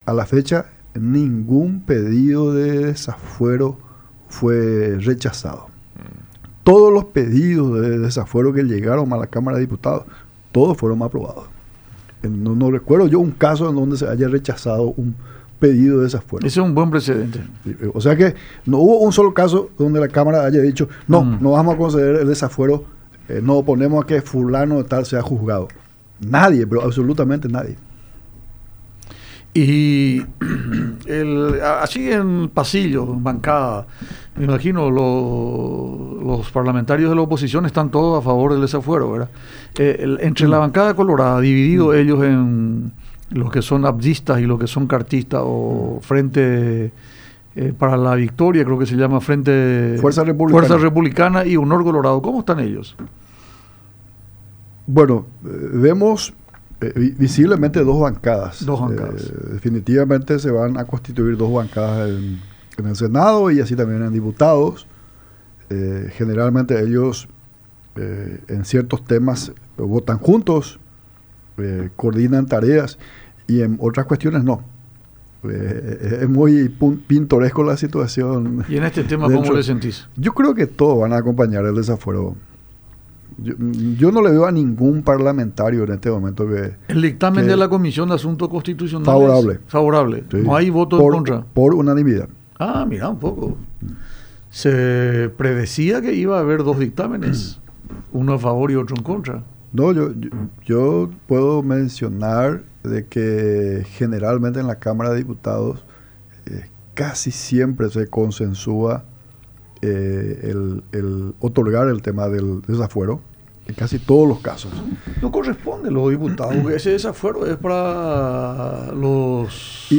El Partido Liberal debe recuperar de esta dura derrota y la única forma es realizando profundas reformas”, enfatizó Buzarquis durante el programa Francamente en su visita a los estudios de Unión TV y radio La Unión.